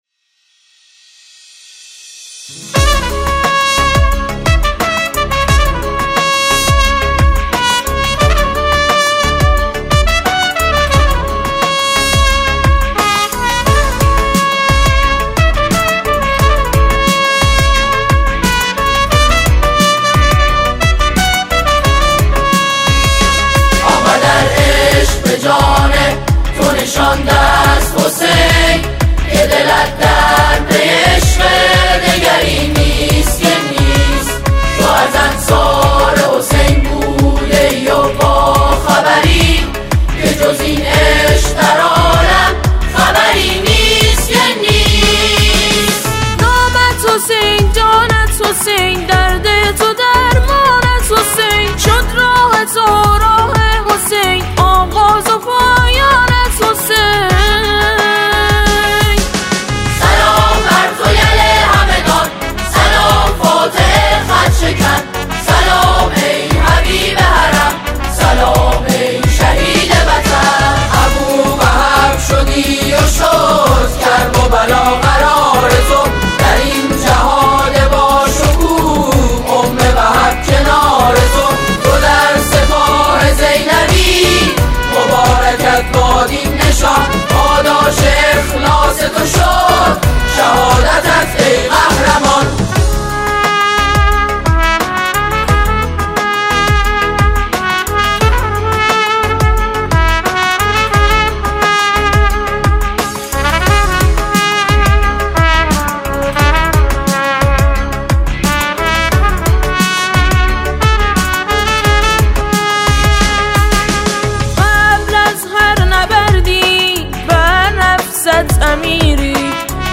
سرودهای شهدا